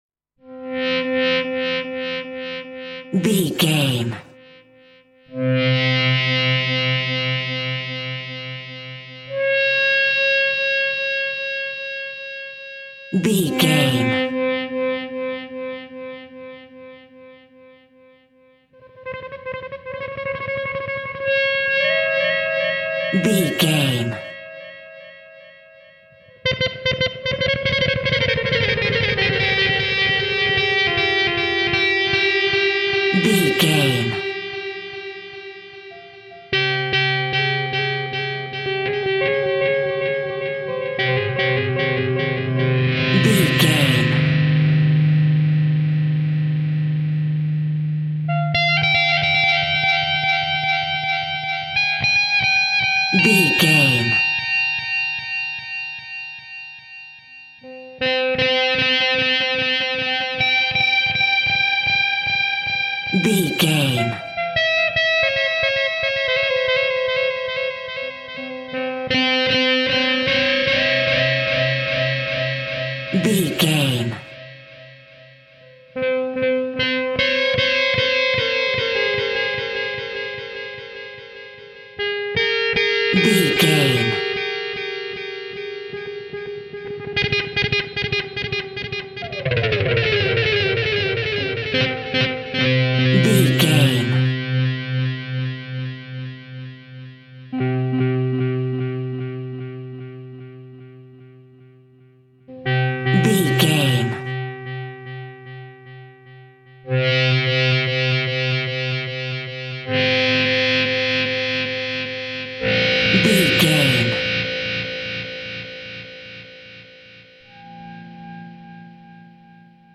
Rocking on Halloween.
Atonal
Slow
tension
ominous
eerie
synthesiser
electric guitar
ambience
pads